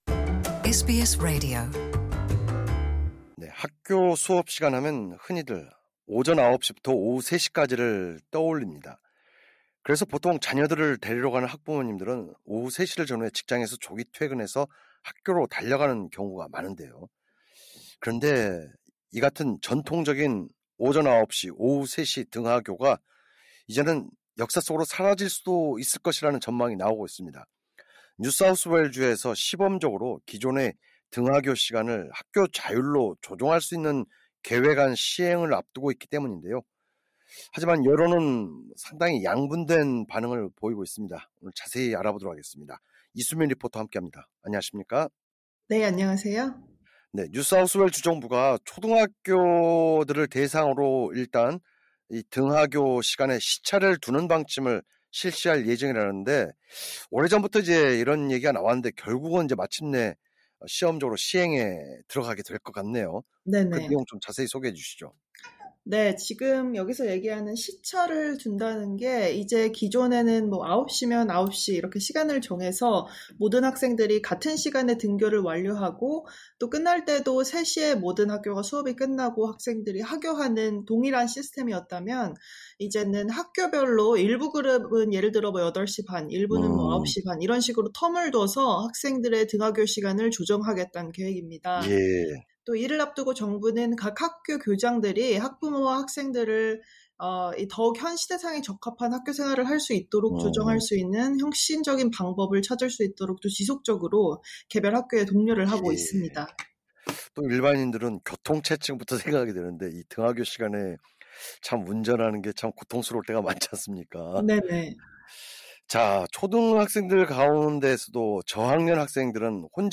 진행자: 뉴사우스웨일즈 주 정부가 초등학교들을 대상으로 등교 및 하교시간에 시차를 두는 방침을 실시할 예정이라는데 어떤 내용인가요?